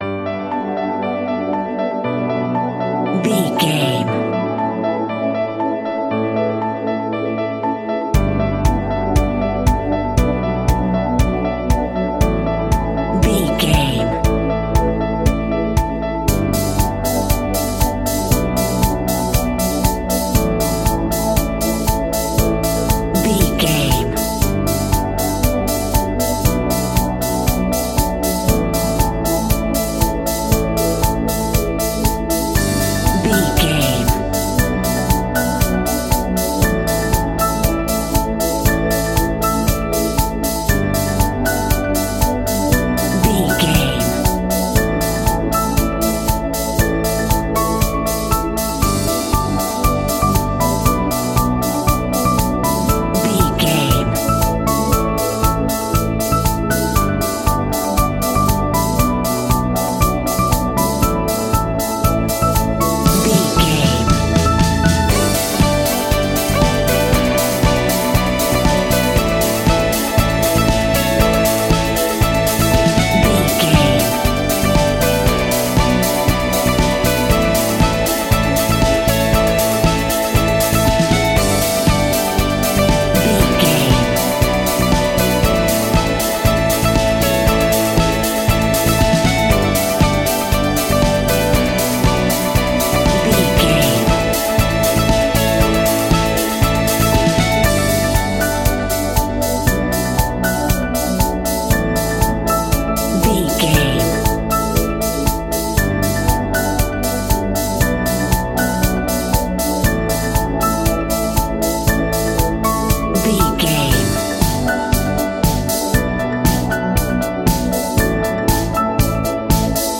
Chart Dance Pop Music.
Ionian/Major
D
groovy
uplifting
energetic
repetitive
bouncy
synthesiser
drum machine
piano
electric guitar
electronic
synth bass